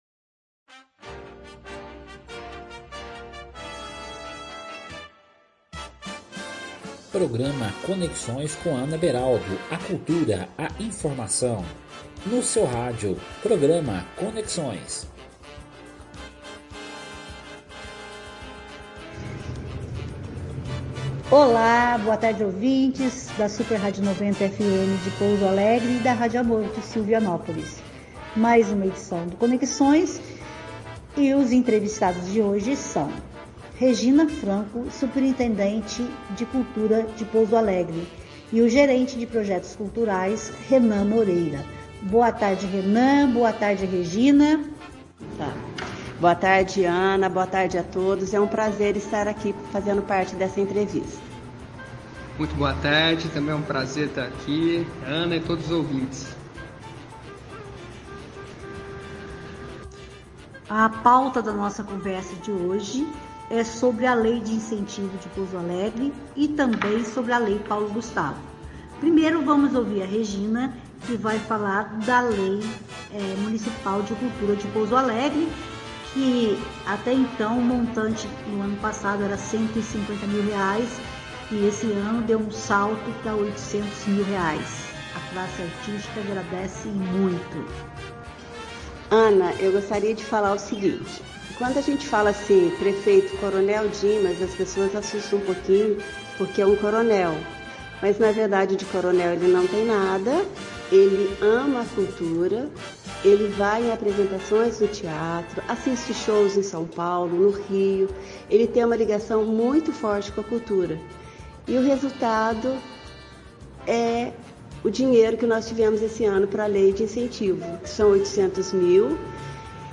Com ênfase para entrevistas na área cultural, o Programa vai ao ar aos domingos, às 13h30.